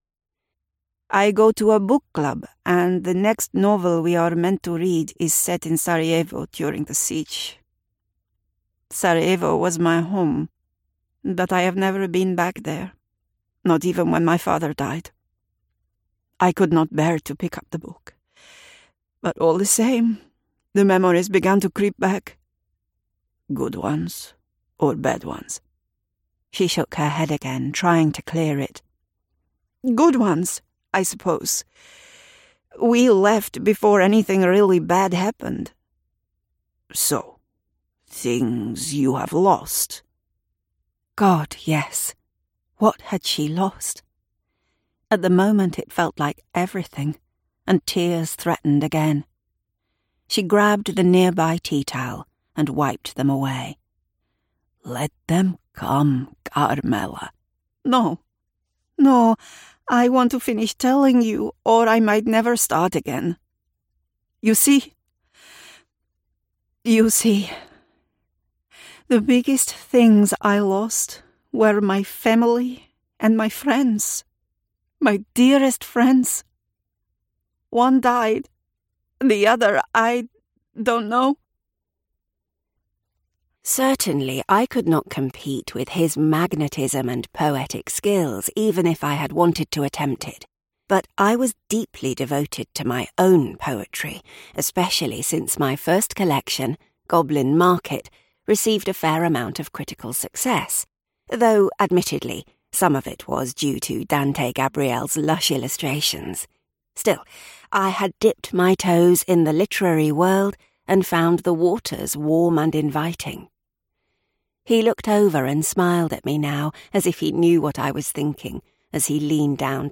Inglês (britânico)
Audiolivros